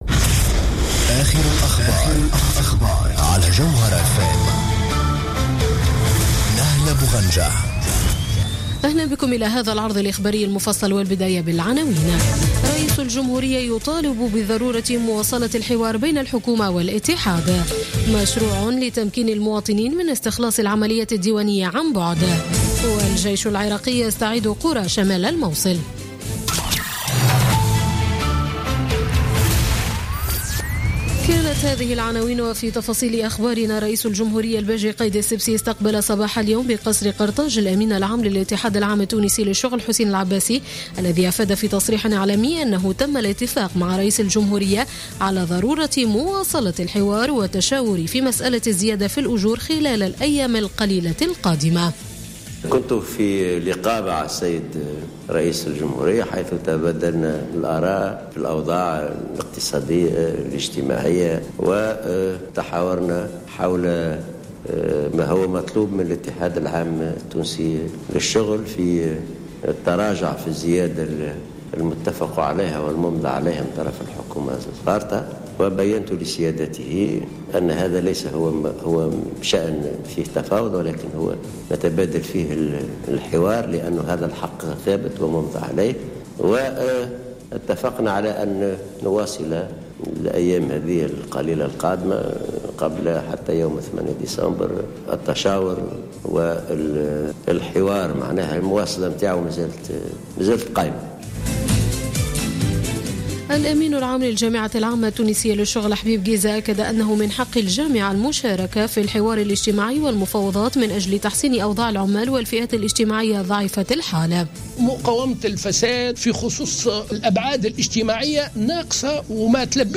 نشرة أخبار السابعة مساء ليوم السبت 3 ديسمبر 2016